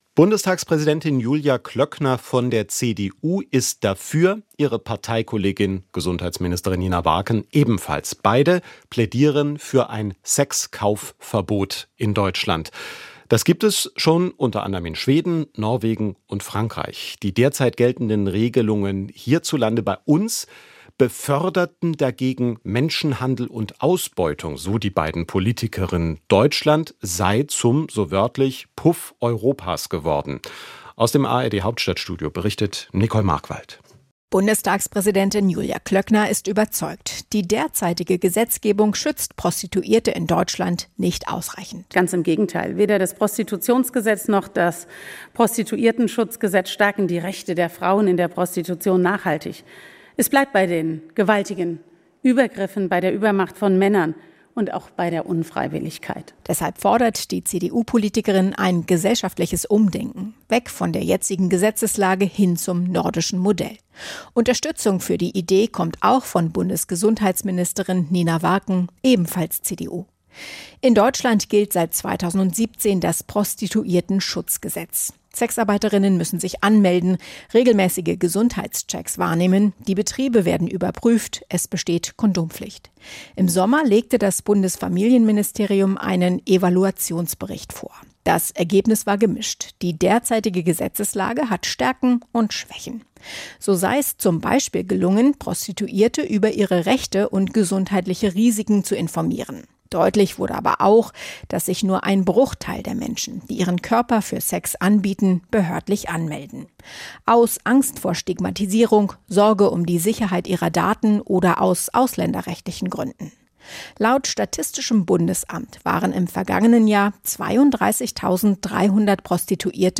welche Alternativen sie sieht, sagt sie im Interview.